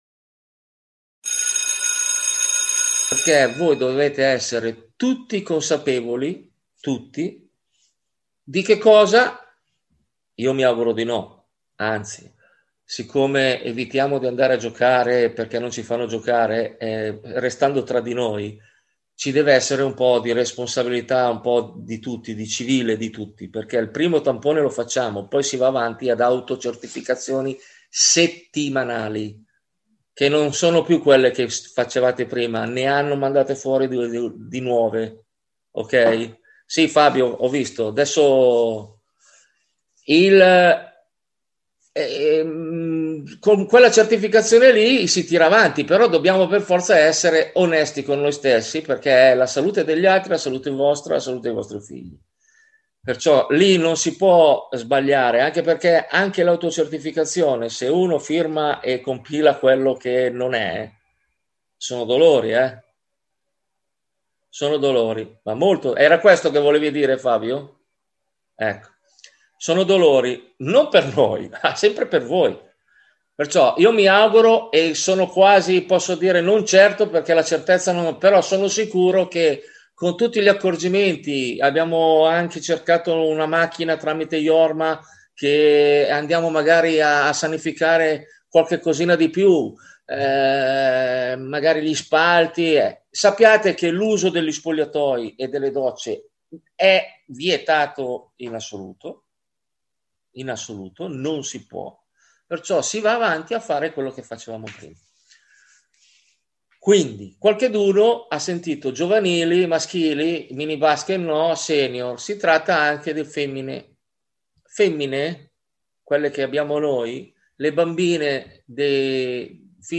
Seconda parte della call su zoom.
Oggi riproponiamo la seconda parte della riunione Zoom del 9 febbraio, per chi se la fosse persa